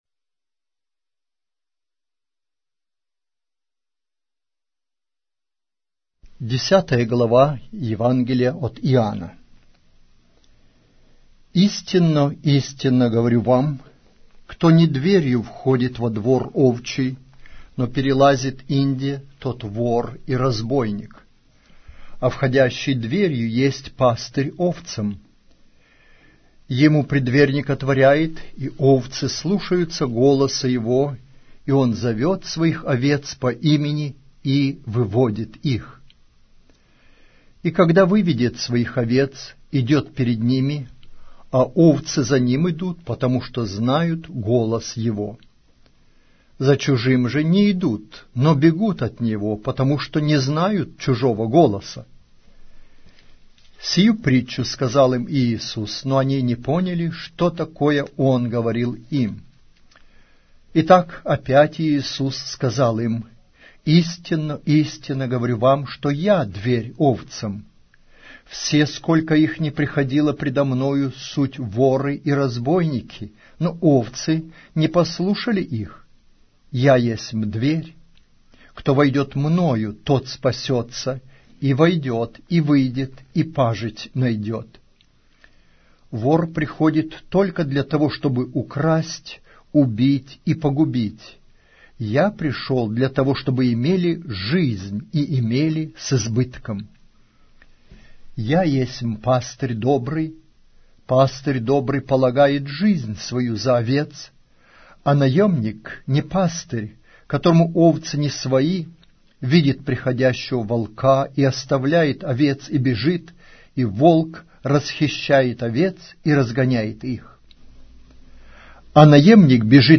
Аудиокнига: Евангелие от Иоанна